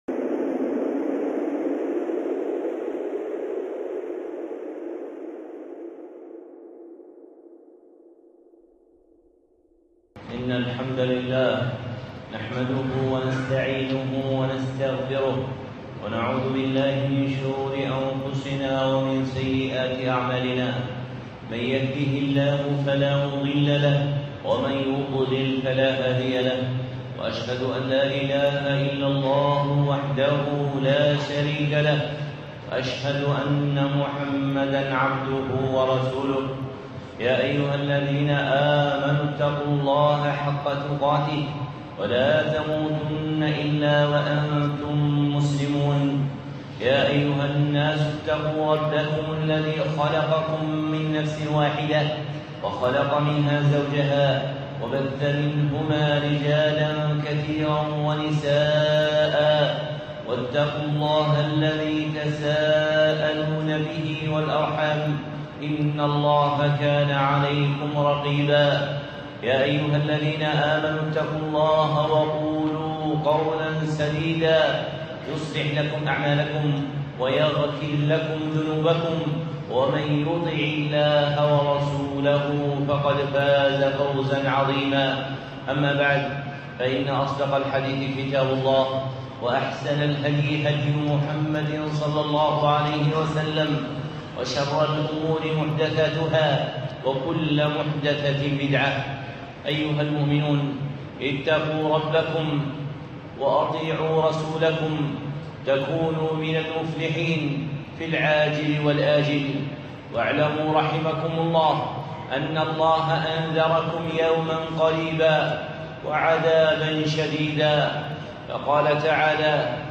خطبة (حلية الحفيظ الأواب)